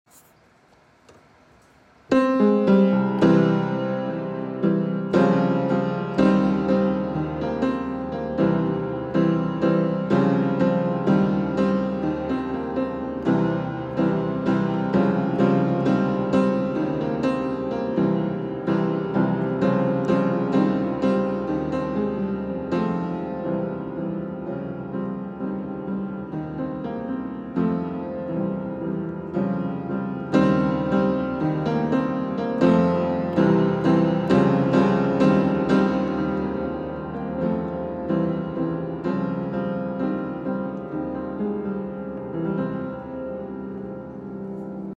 piano cover